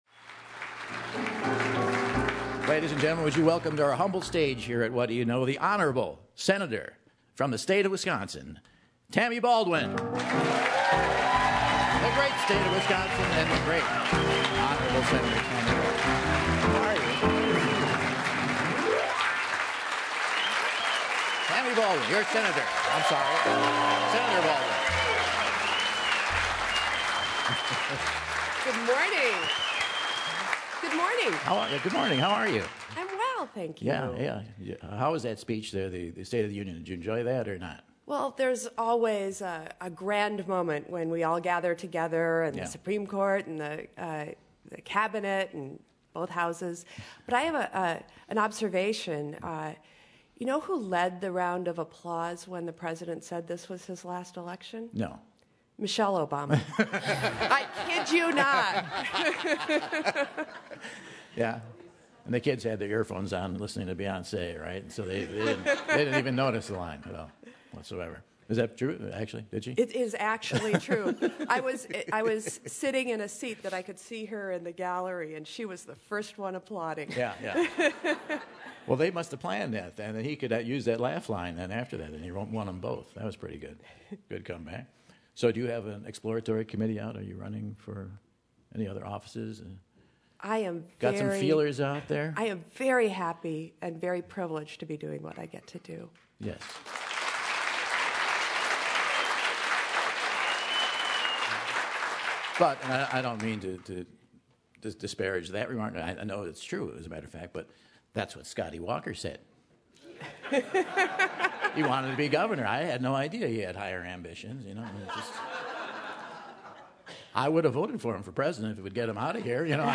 Fresh off a consecutive Golden Gavel win, Senator Tammy Baldwin takes the Terrace stage to chat about the State of the Union, Senate office space and her secret Santa...Ted Cruz!